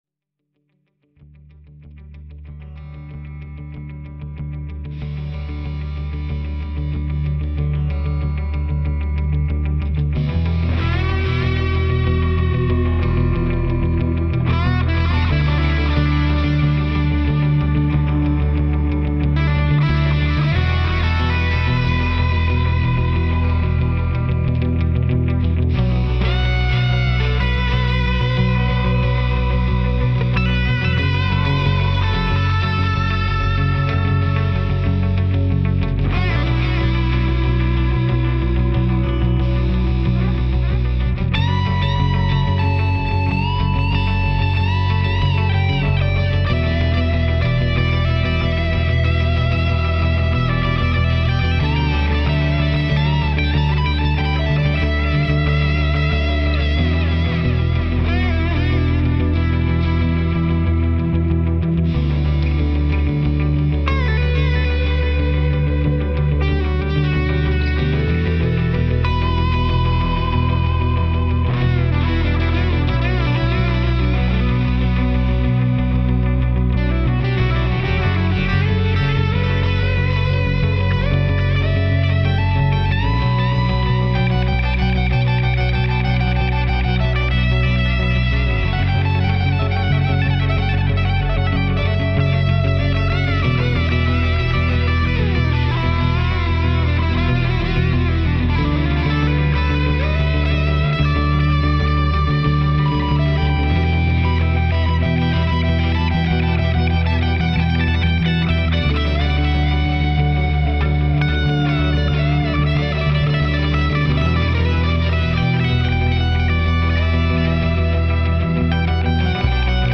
My original guitar instrumental "highlight"